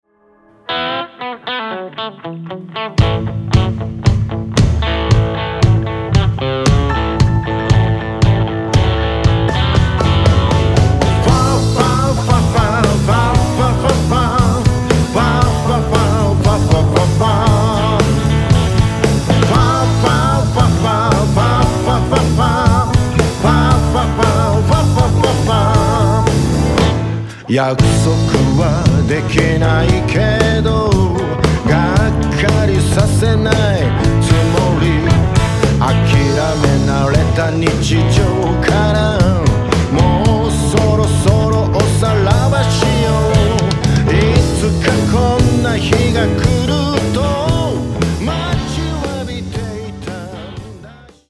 ジャンル：フォーク/ロック